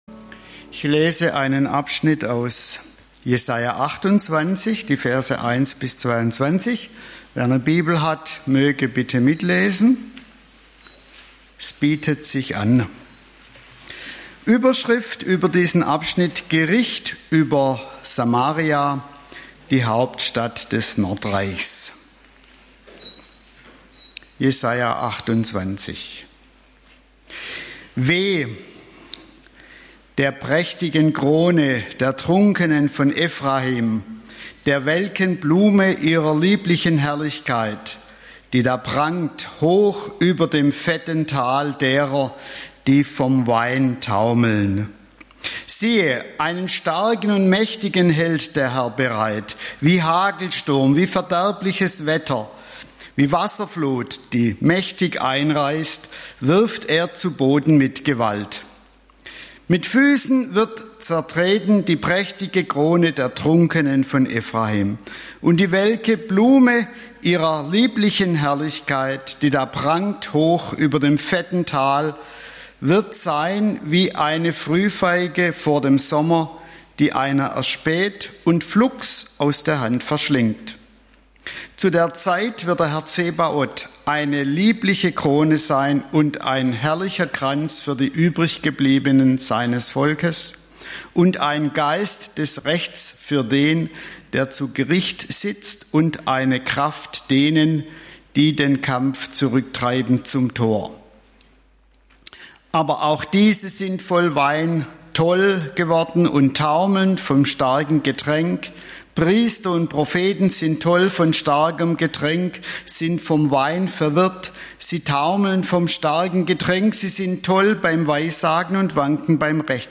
Jetzt ist er da - der Starke (Jes. 28, 1-22) - Bibelstunde ~ LaHö Gottesdienste Podcast